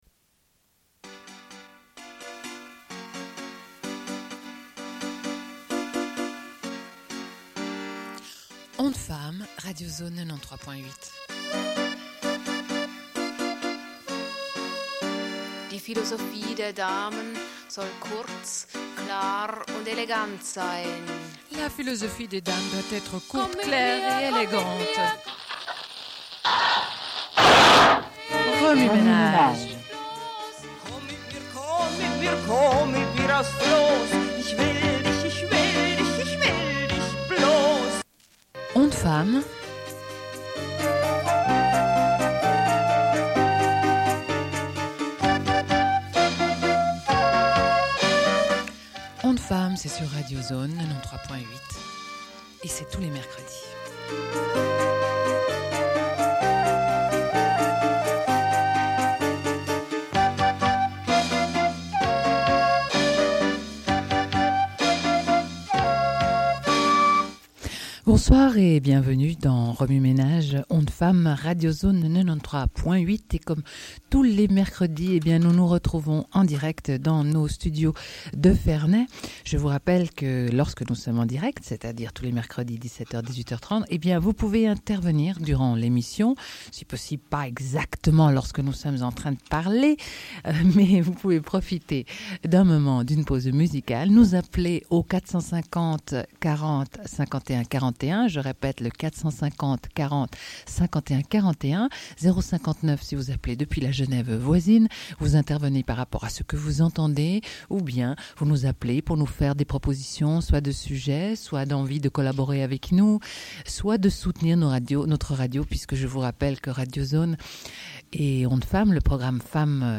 Sommaire de l'émission : rencontre avec cinq femmes kosovares, diffusion d'entretiens.
Une cassette audio, face A
Radio